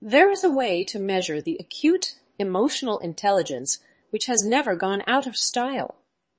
tortoise-tts - (A fork of) a multi-voice TTS system trained with an emphasis on quality